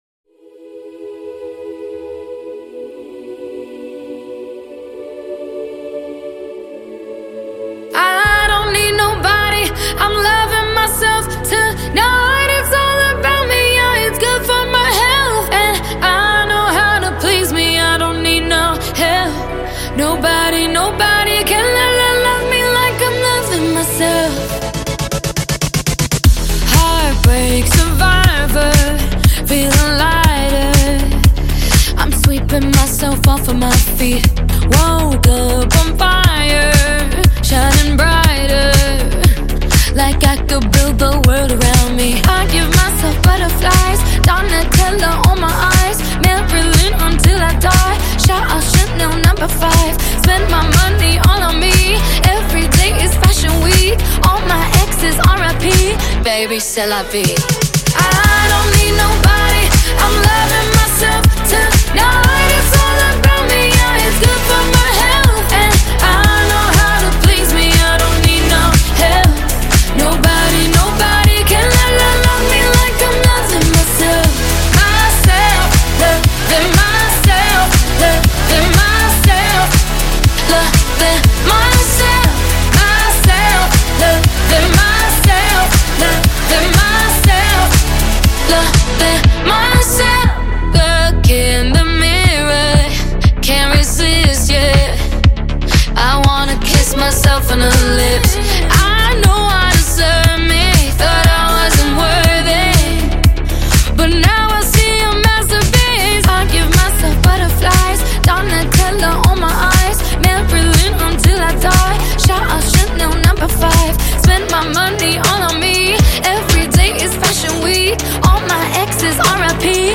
ژانر موزیک : Electro Pop